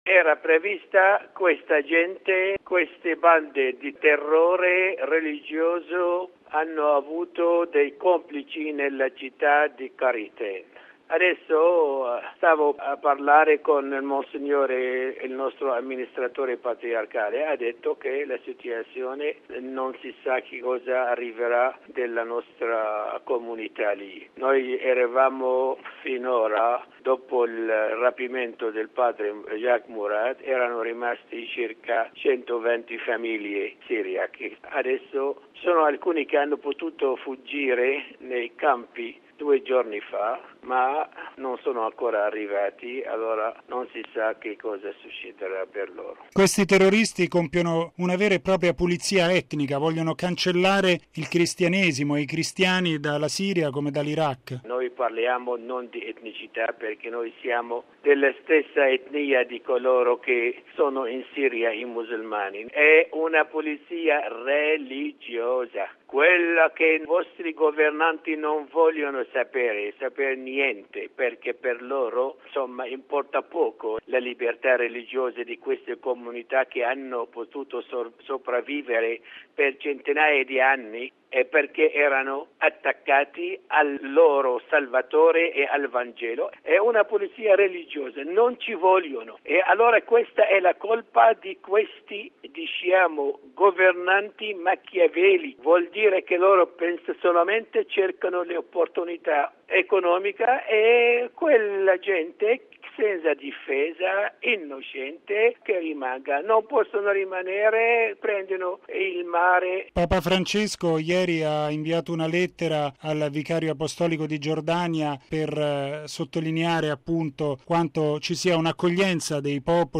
la drammatica testimonianza del patriarca della Chiesa siro-cattolica Ignace Youssif III Younan